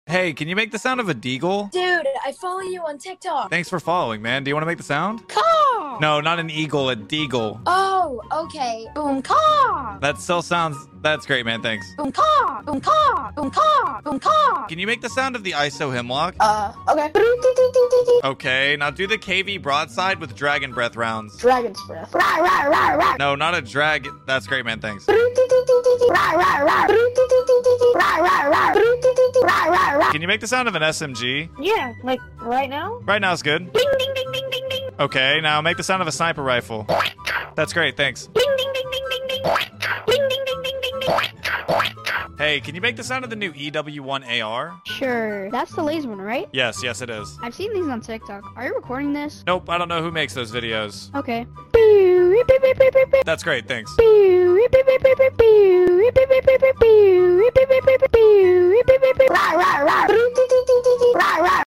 Warzone Gun Noise Compilation pt. sound effects free download